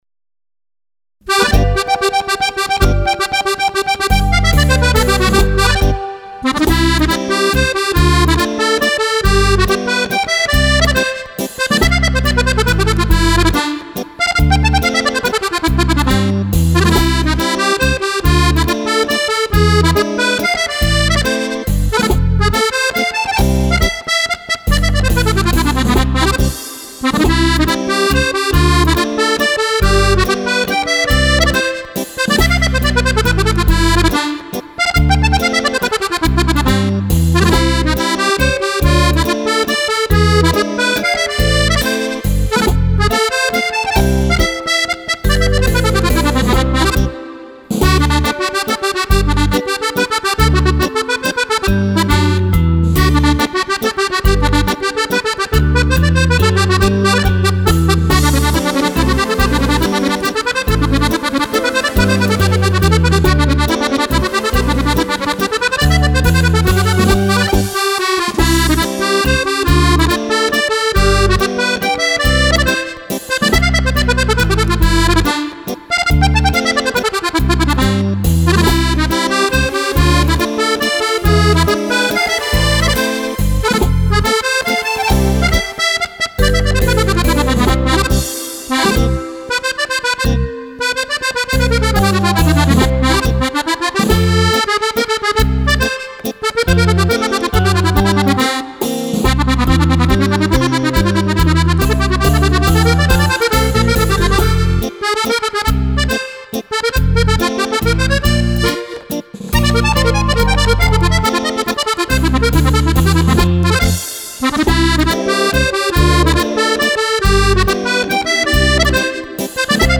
Mazurka per Fisarmonica